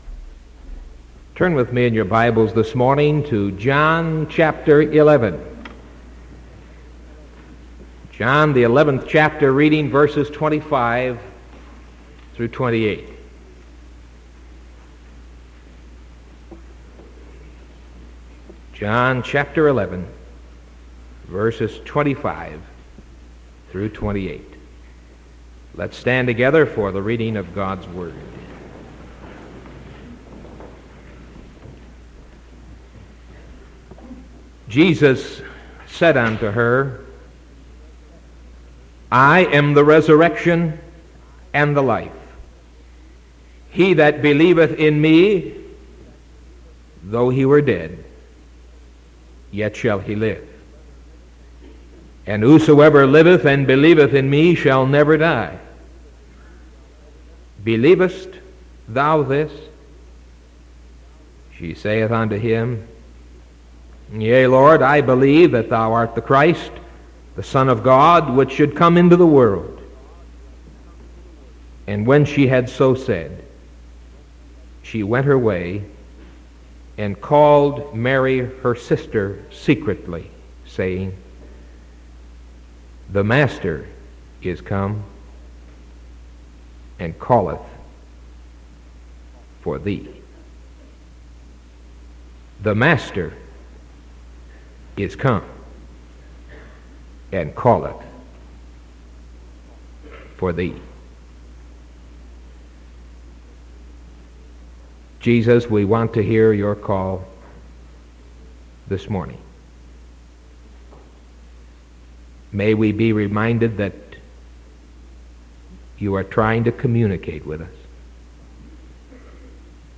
Sermon from November 10th 1974 AM